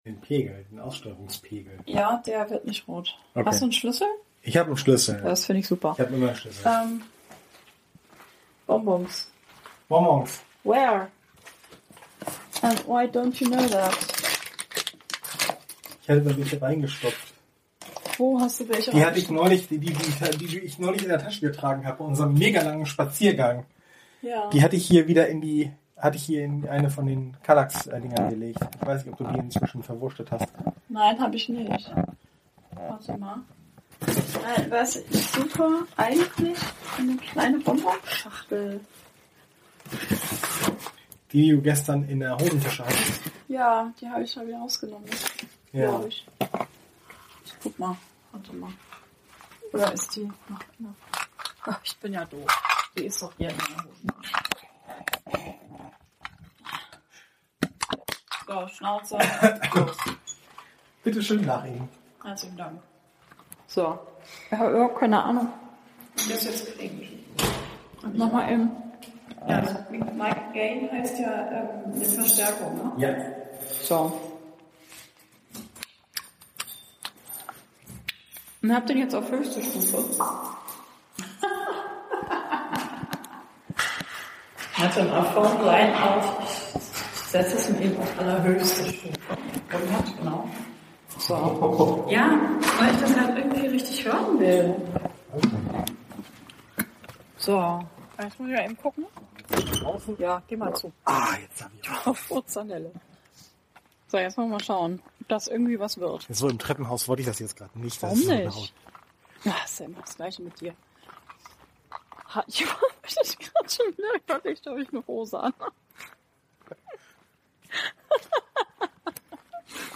Dies ist ein Test mit einem anderen Aufnahmegerät als Beaker. Weil wir es können.Dies wurde mit einem Zoom H2N aufgenommen, wieder draußen und hauptsächlich als Test.